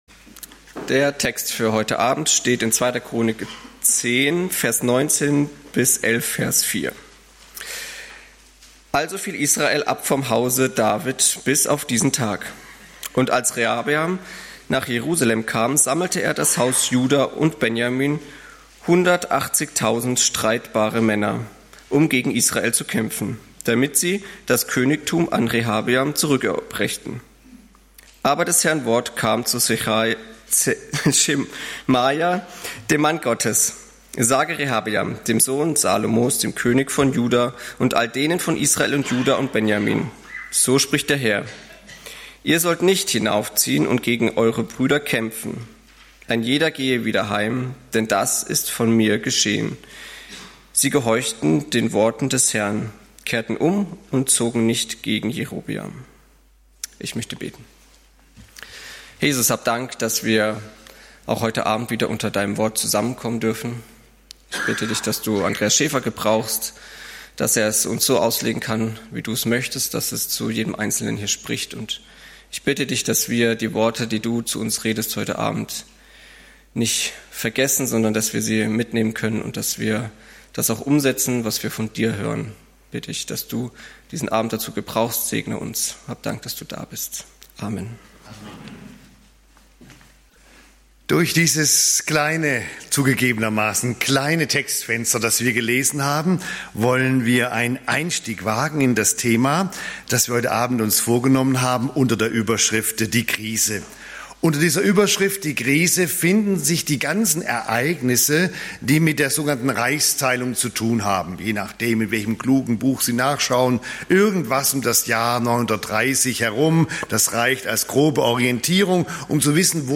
2. Chronik - Die Krise - Bibelstunde ~ LaHö Gottesdienste Podcast